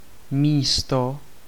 Ääntäminen
IPA: [miːstɔ]